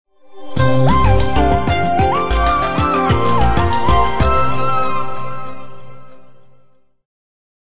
веселые
без слов
победные
электронные